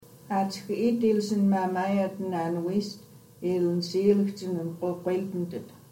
Pronunciation (Voiced by Jeannette Armstrong)